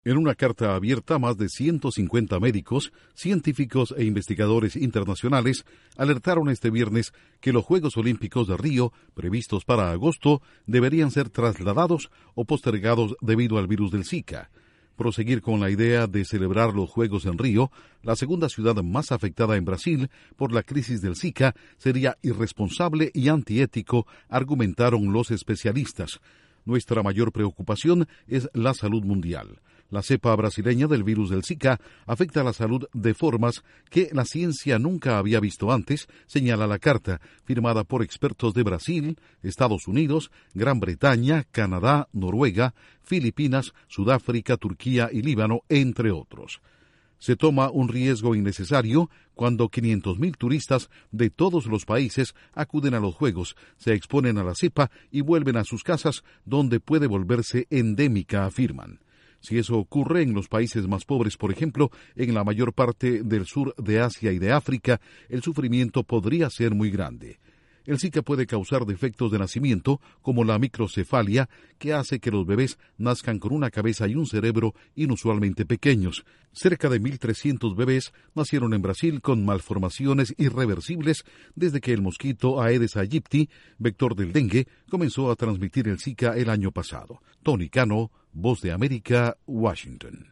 Un grupo internacional de expertos pide que se trasladen o posterguen Juegos Olímpicos de Rio debido al virus del Zika. Informa desde la Voz de América